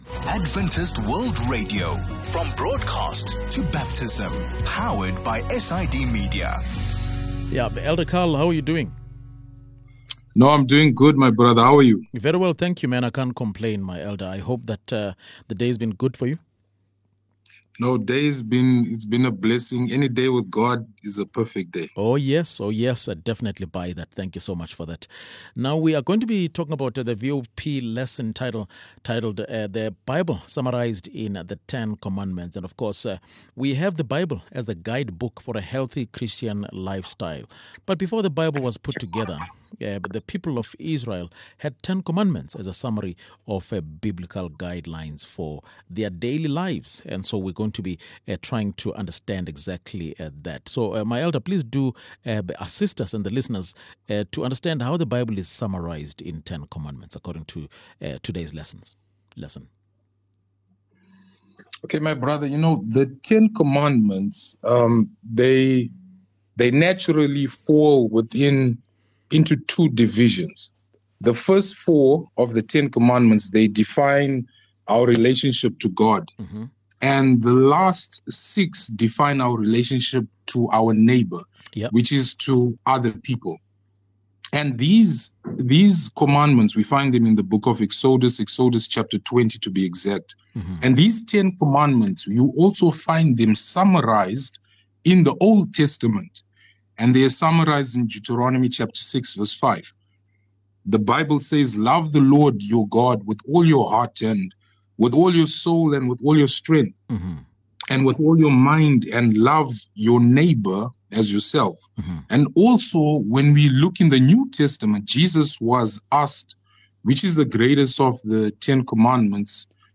2 Jul VOP Lesson | The Bible Summarized in the Ten Commandments